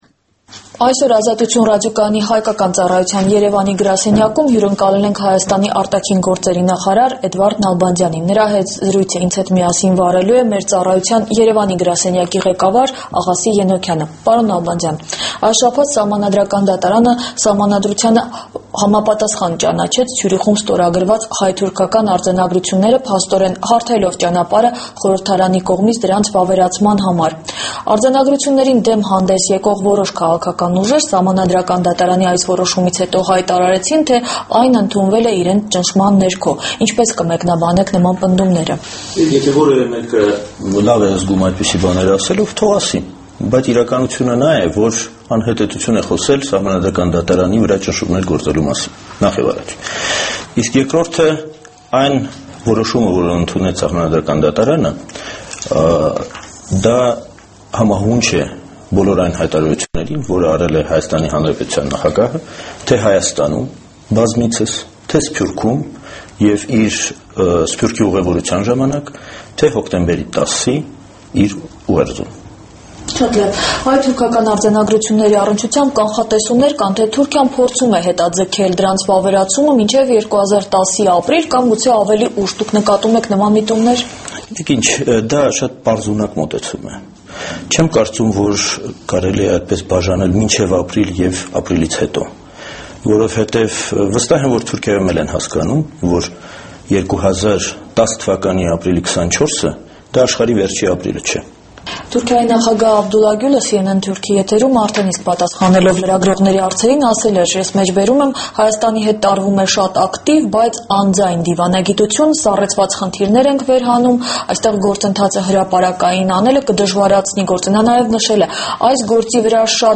Հայաստանի արտգործնախարար Էդվարդ Նալբանդյանի հարցազրույցը «Ազատություն» ռադիոկայանին